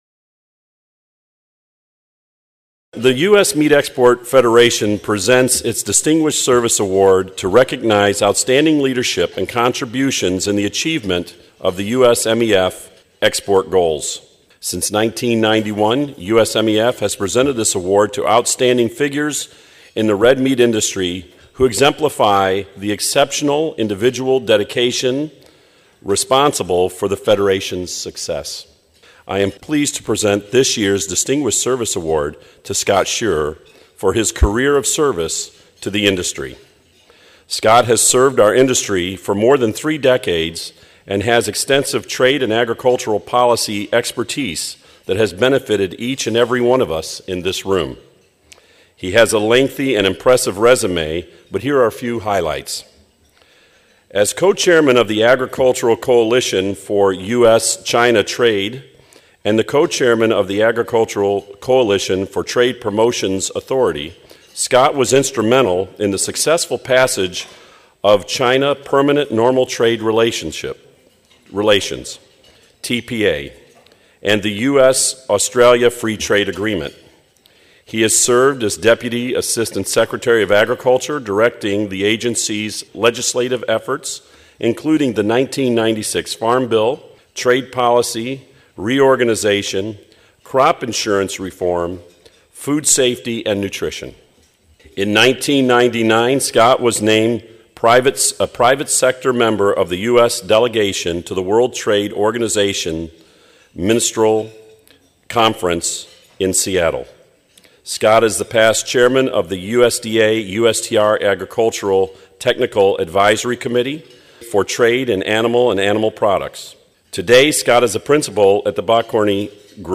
at the USMEF Strategic Planning Conference in Fort Worth